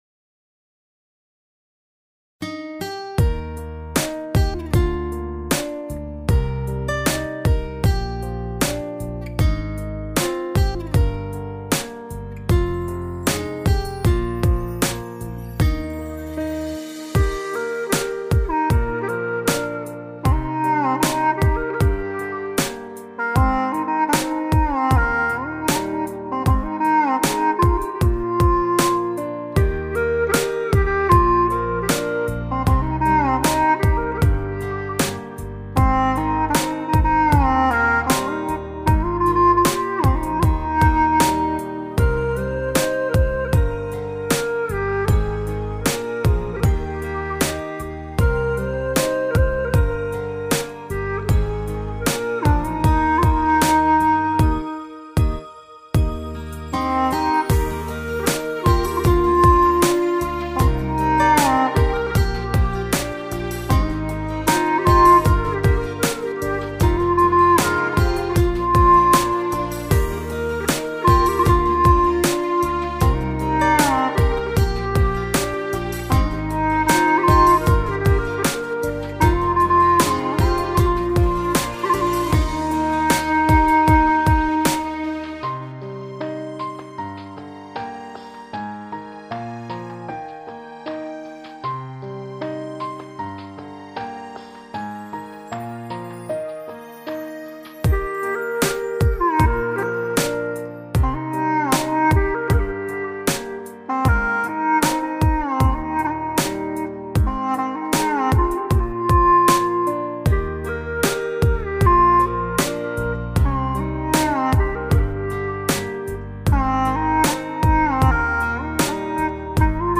调式 : 降E 曲类 : 流行